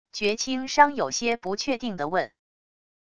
绝倾殇有些不确定的问wav音频
绝倾殇有些不确定的问wav音频生成系统WAV Audio Player